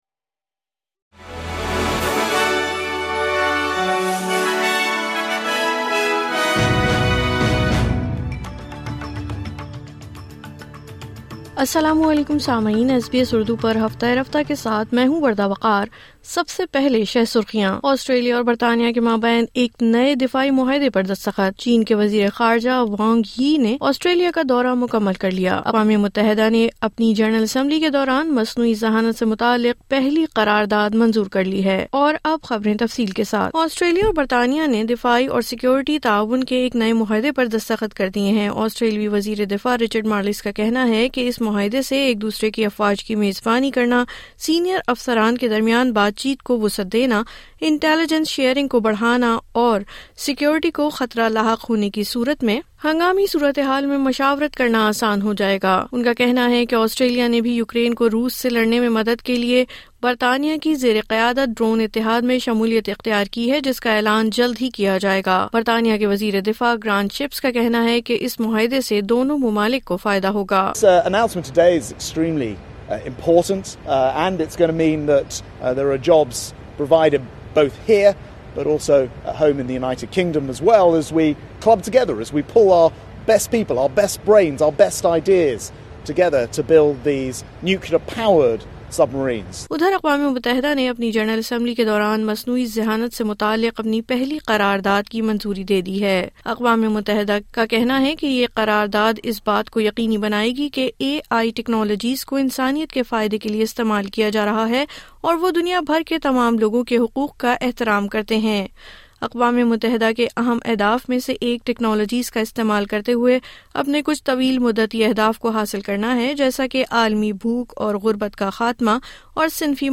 مزید تفصیل کے لئے سنئے اردو خبریں